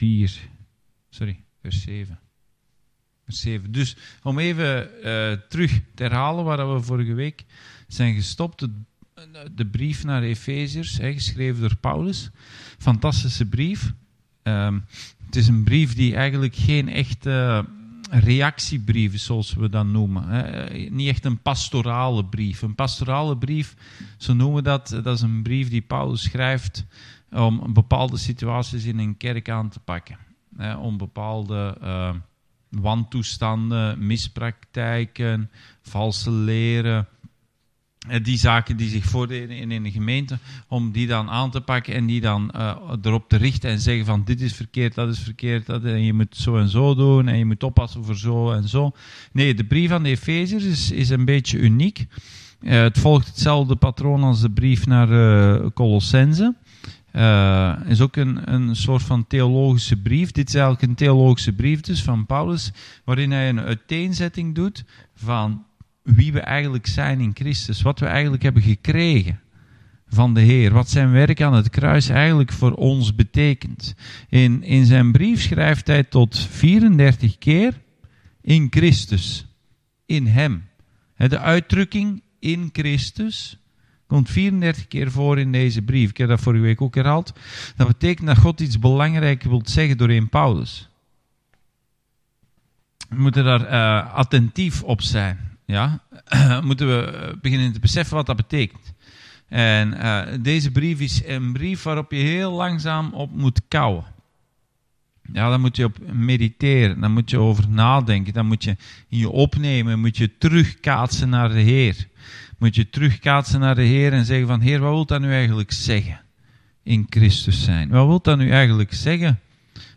Bijbelstudie: brief naar efeze – 2